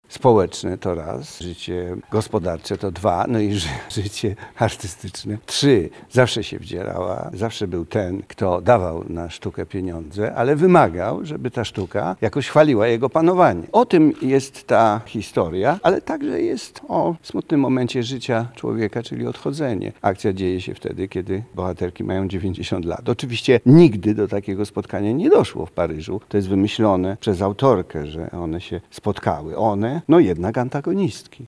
– To sztuka opowiadająca o tym, jak polityka wdziera się w nasze życie – mówi reżyser Mikołaj Grabowski.